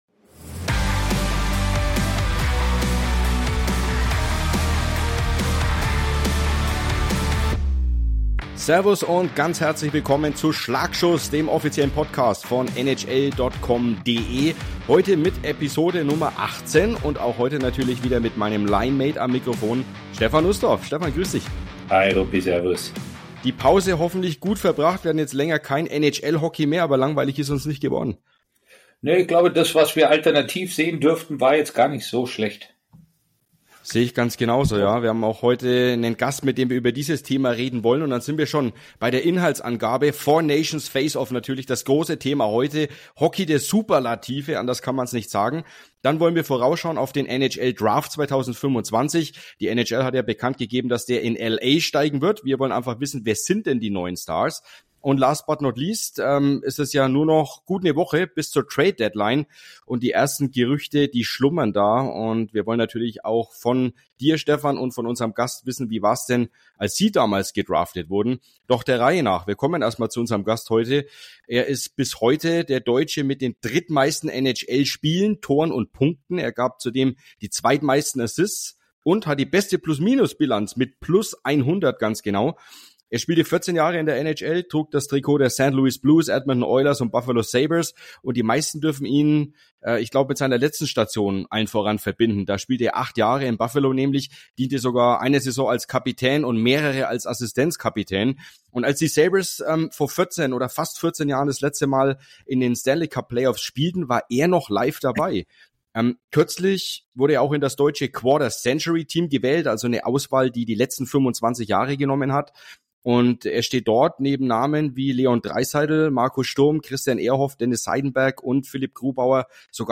Zu Gast ist mit Jochen Hecht einer der erfolgreichsten deutschen NHL-Profis aller Zeiten, der bei den Buffalo Sabres sogar als Kapitän diente. Für Begeisterung bei allen Beteiligten sorgte der 4 Nations Face-Off.